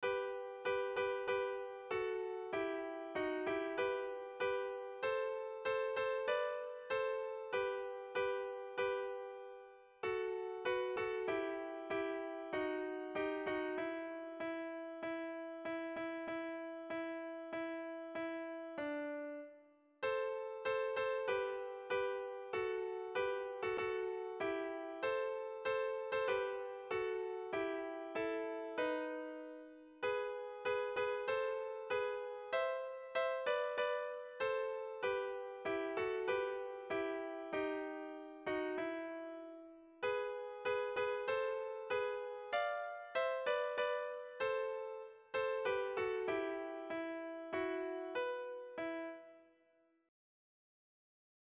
Erlijiozkoa
Zortziko handia (hg) / Lau puntuko handia (ip)